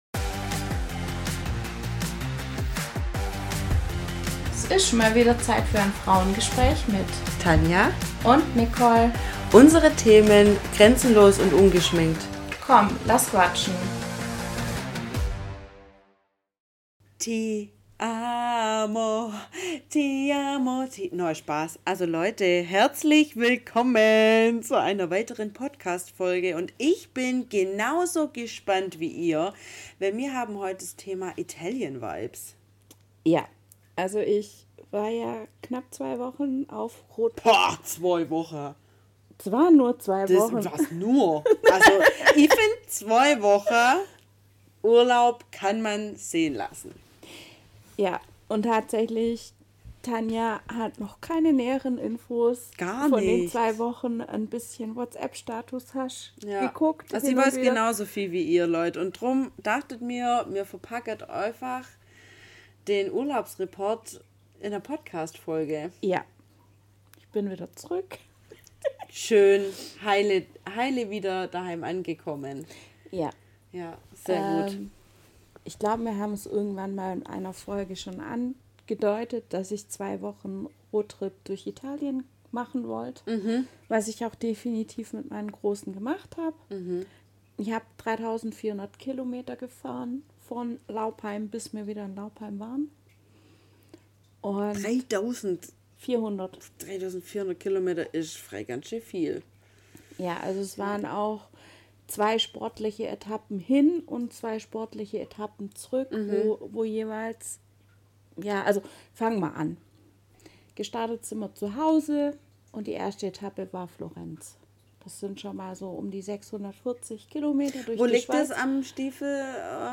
Es ist mal wieder Zeit für ein FRAUENGESPRÄCH…